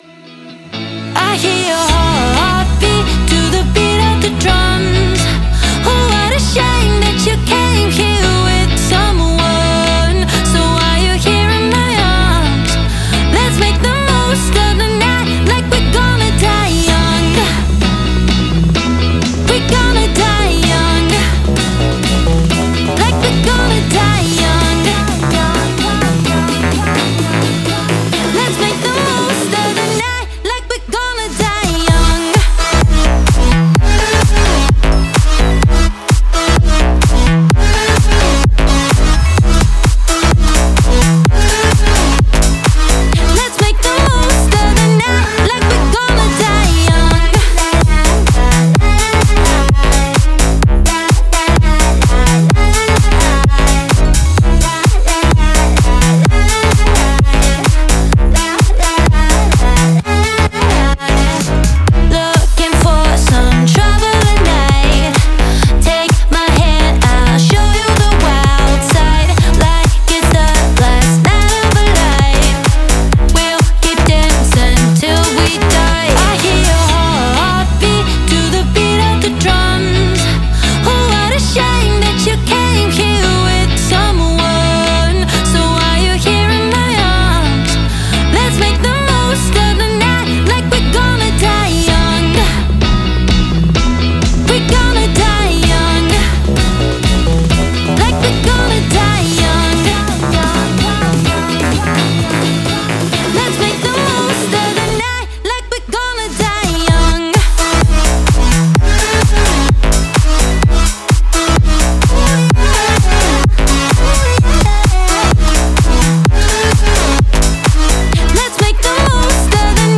это энергичная поп-песня в жанре EDM